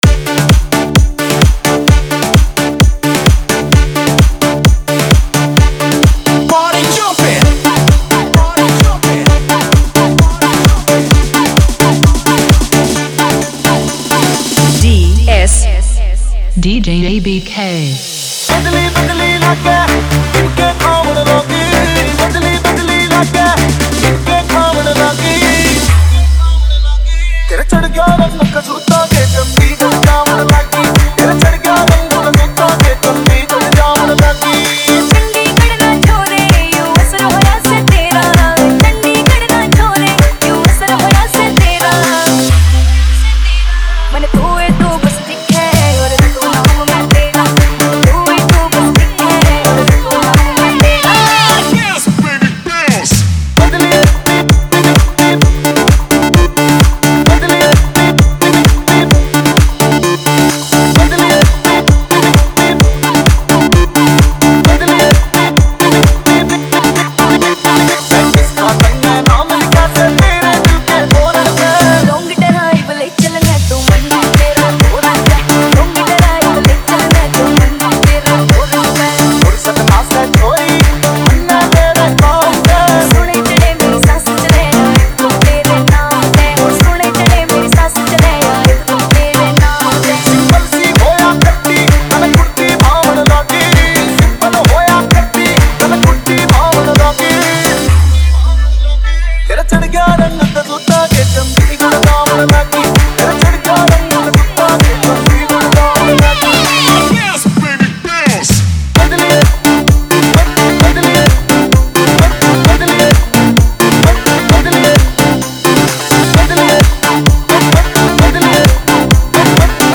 Haryanvi DJ Remix Songs